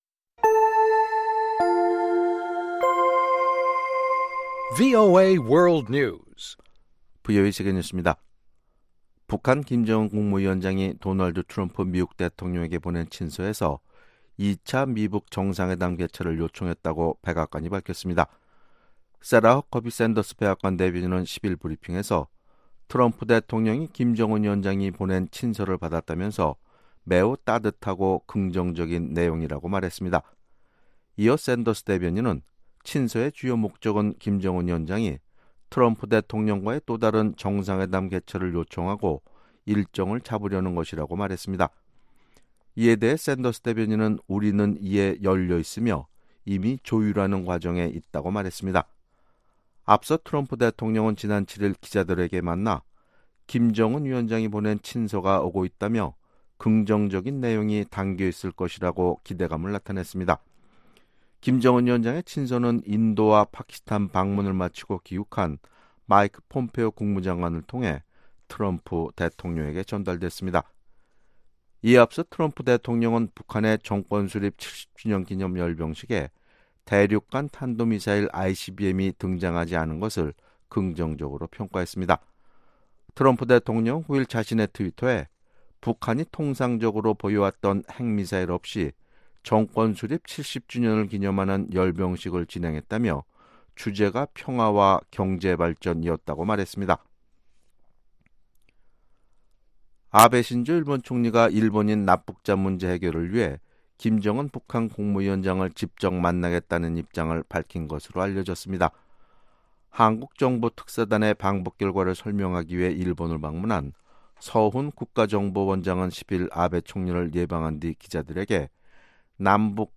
VOA 한국어 아침 뉴스 프로그램 '워싱턴 뉴스 광장' 2018년 9월 11일 방송입니다. 트럼프 대통령은 북한 열병식에 ICBM이 등장하지 않는데 대해 긍정적으로 평가했습니다.